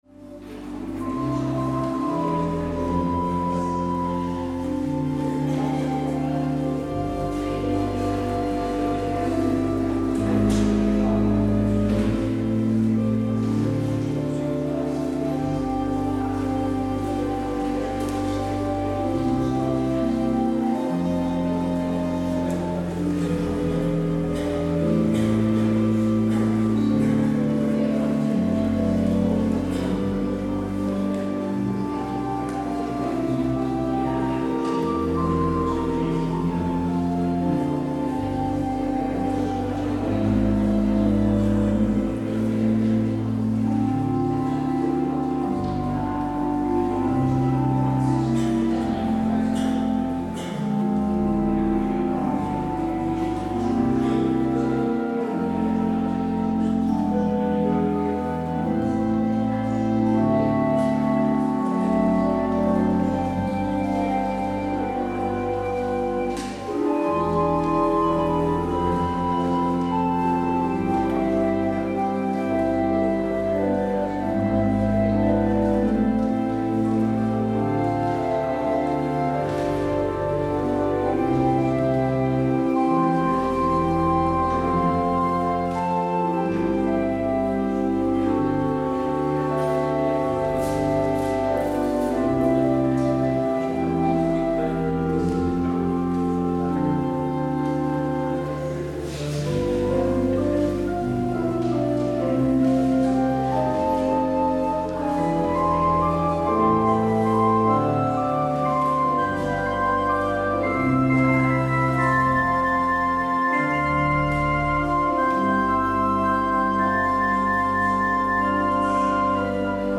 Luister deze kerkdienst terug
Daar de Alle-Dag-Kerk in Amsterdam door de lock down tijdelijk gesloten is, zenden wij een herhaling uit van de dienst gehouden op 10 januari 2018.